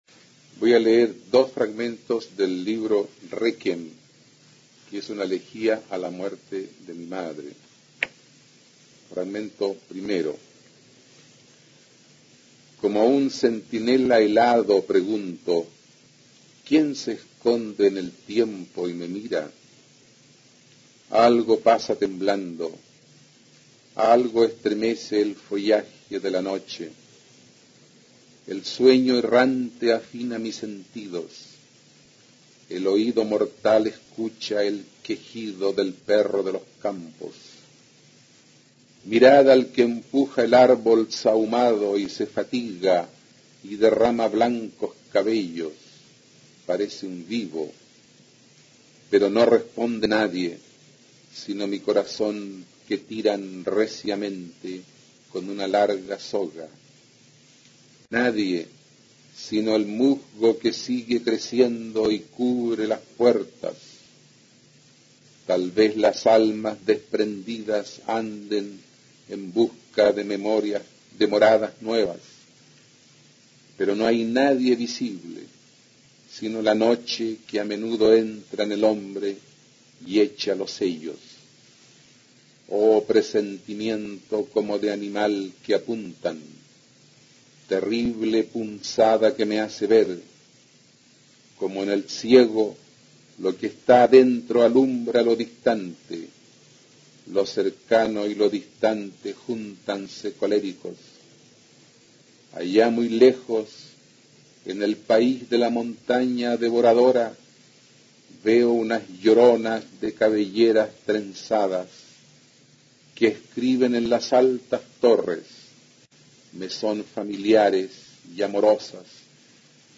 A continuación se puede escuchar a Humberto Díaz-Casanueva, destacado autor de las vanguardias hispanoamericanas y Premio Nacional de Literatura en 1971, leyendo un fragmento de su libro "Réquiem" (1945), obra que constituye una elegía a la muerte de su madre.